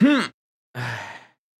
casting_fail.wav